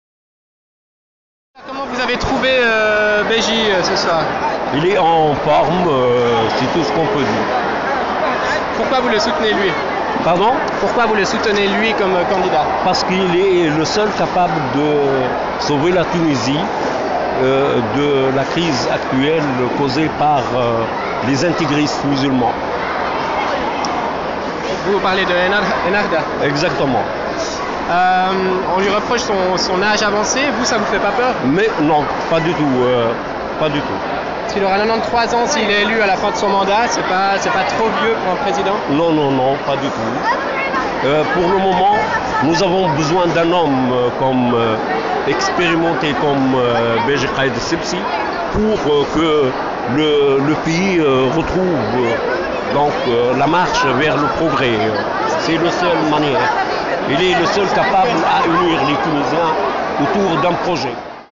Réactions à la sortie du meeting de Béji Caïd Essebsi